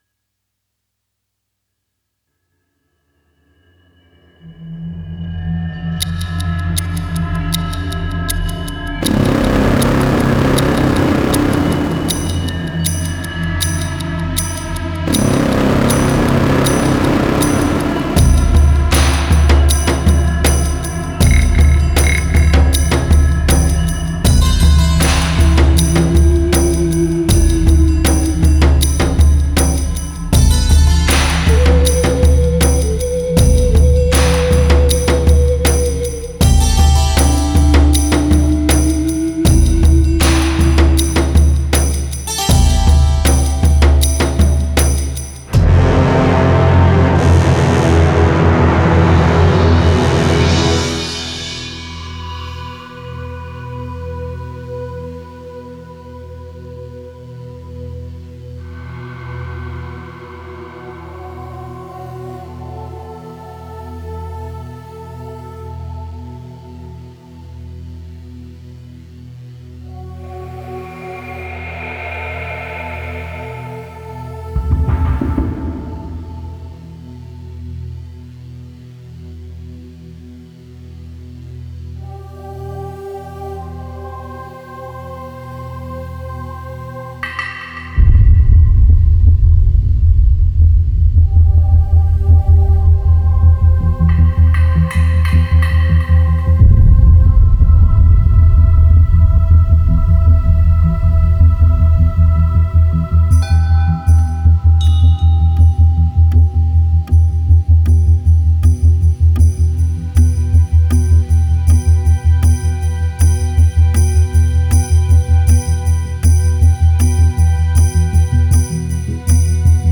纯音雅乐